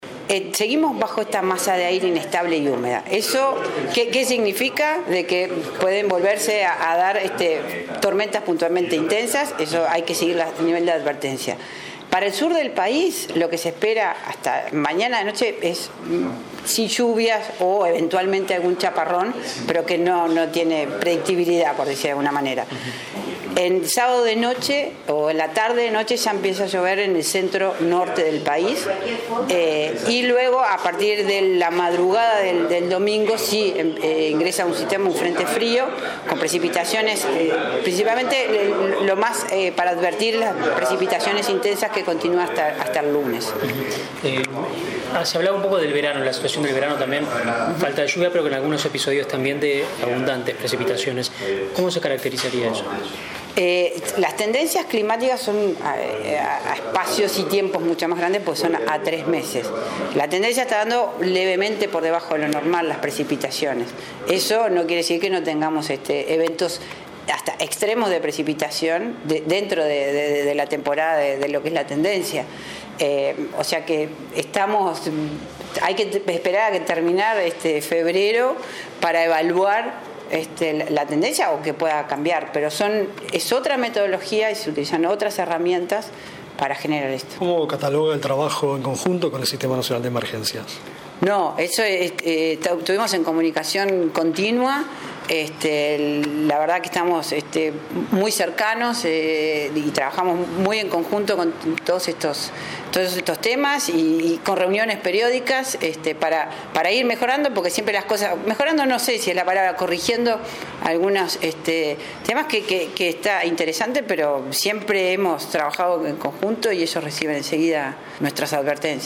En rueda de prensa Renom sostuvo que la posibilidad de que ocurrieran "tormentas puntualmente intensas" estaba prevista y que la advertencia preveía que los fenómenos pudieran ocurrir en alguno de los departamentos de la franja costera desde Colonia hasta Rocha.